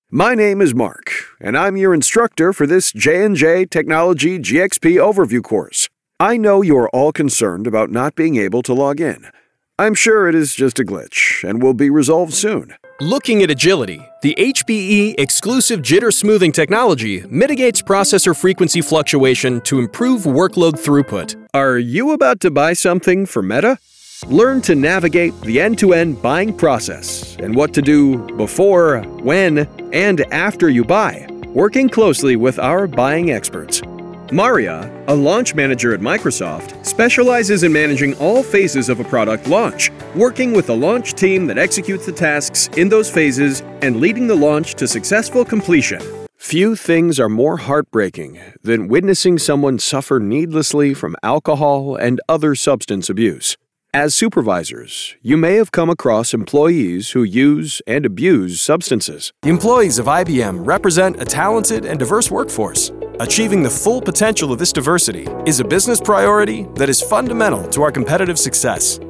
E-Learning Showreel
Male
Authoritative
Confident